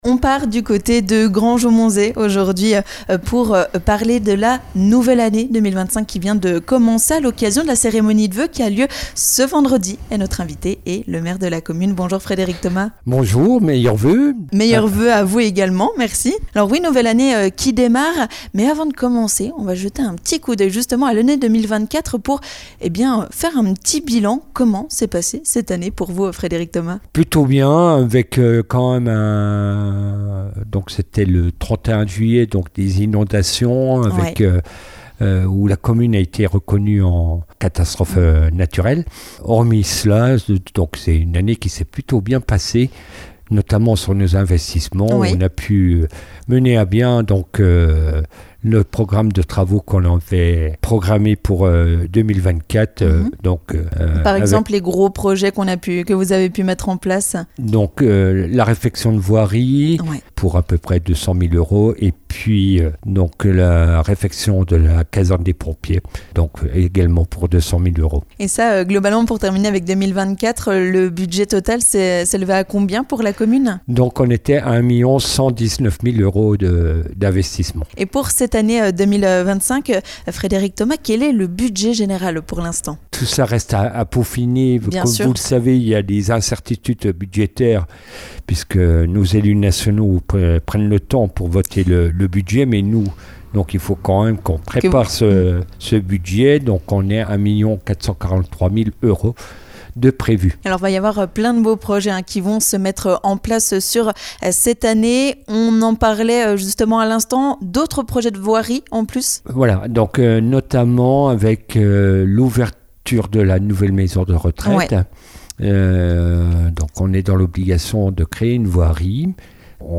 L'occasion pour nous d'aller à la rencontre du maire, Frédéric Thomas, afin de savoir quels seront les projets pour l'année 2025. Autant avec notamment la possible création de nouvelles infrastructures sportives, une nouvelle offre de services à la population ou encore des travaux de voirie.